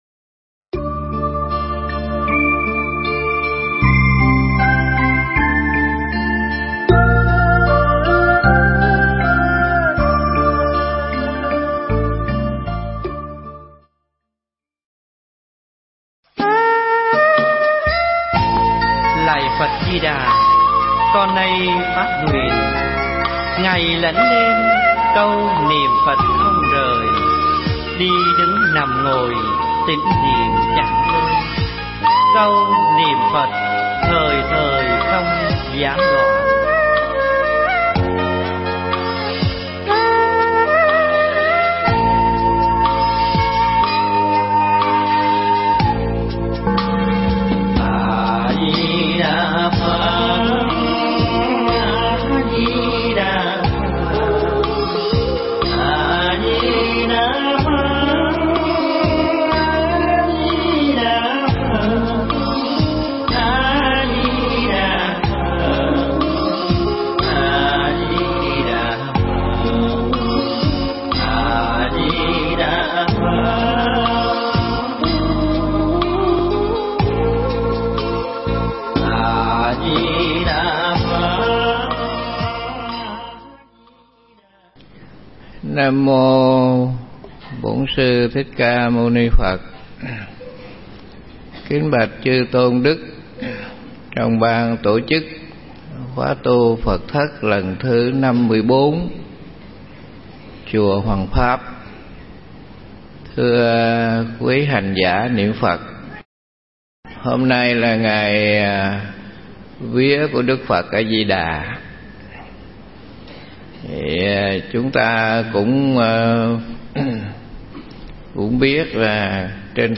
Nghe Mp3 thuyết pháp Thuốc Hay